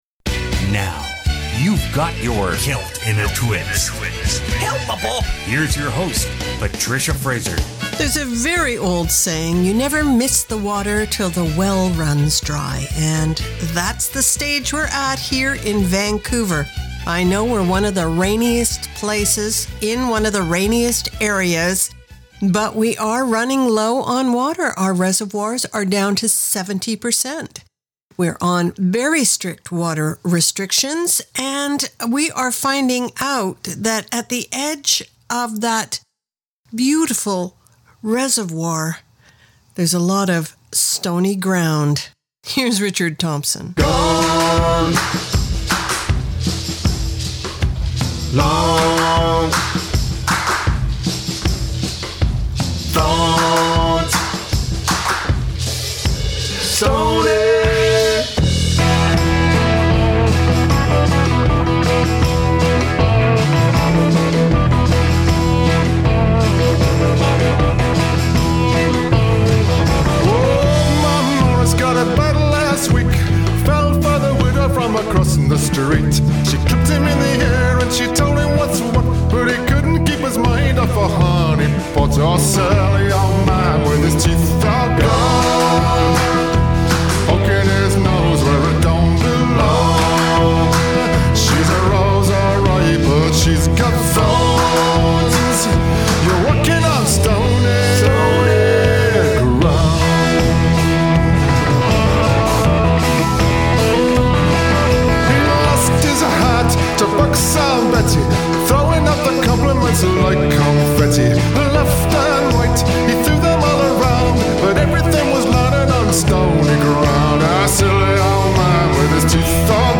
Canada's Contemporary Celtic Radio Hour
a packed hour of worldbeat!